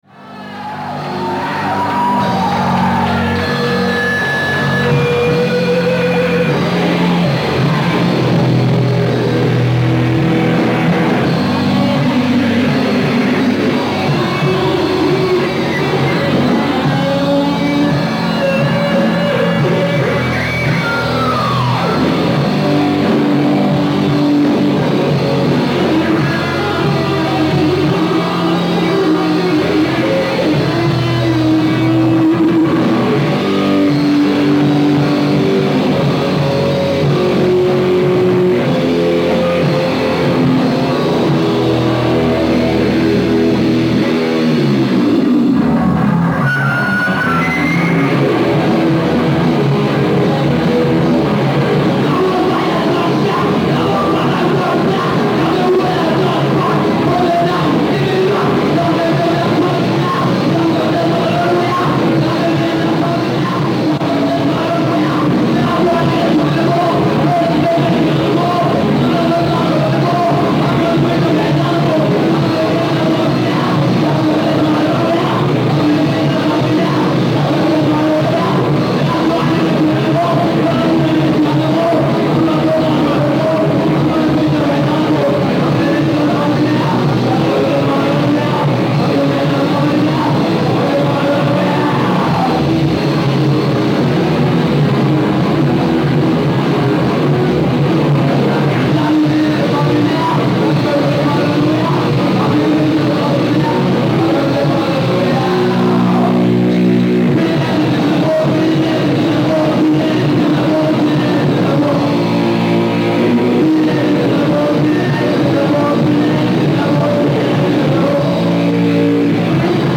Video from CEC Philadelphia